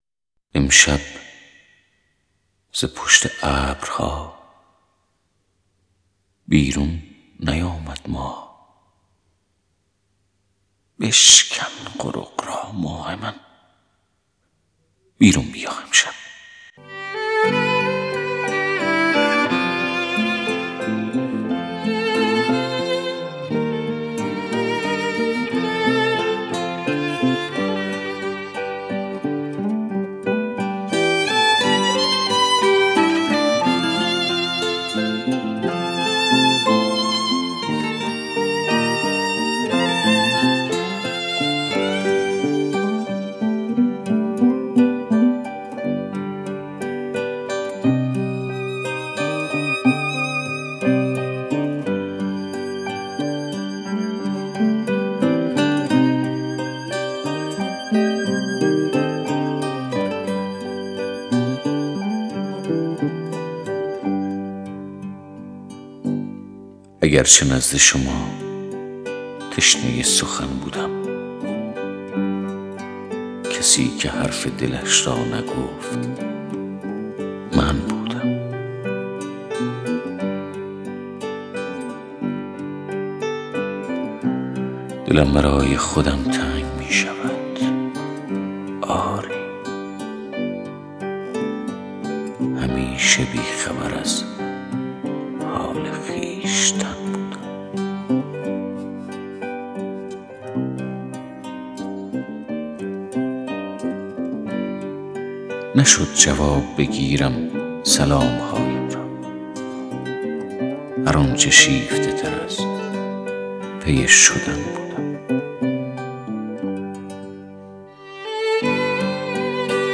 دانلود دکلمه دلم خوش است با صدای پرویز پرستویی
گوینده :   [پرویز پرستویی]